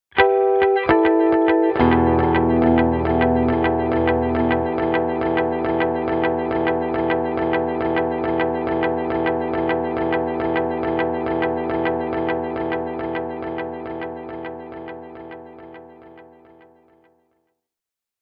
Tältä Flashback X4:n efektityypit kuulostavat: